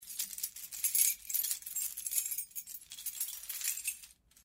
Звуки отмычки
Звук связки ключей